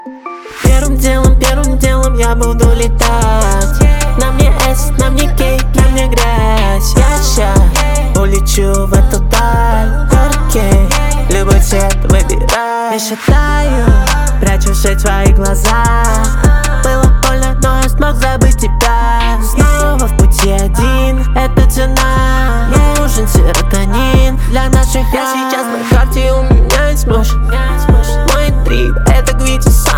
Жанр: Русская поп-музыка / Русский рок / Русские